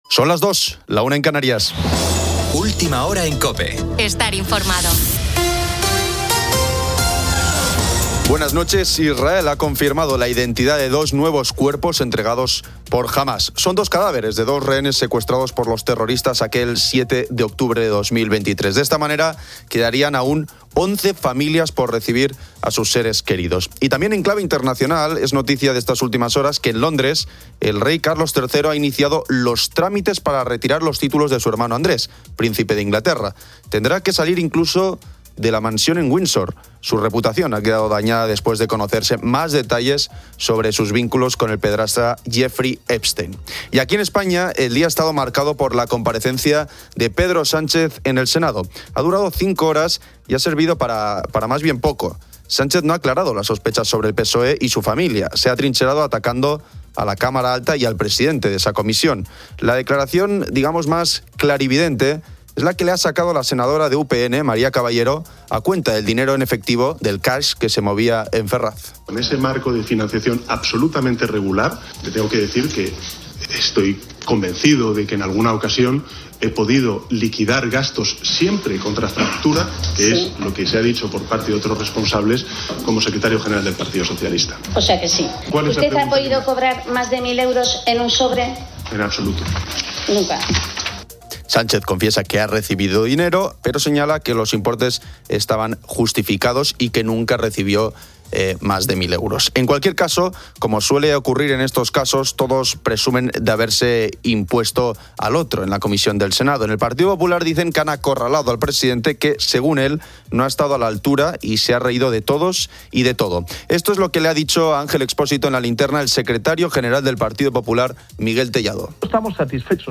los oyentes comparten sus costumbres por Halloween y el Día de Todos los Santos, incluyendo dulces como buñuelos.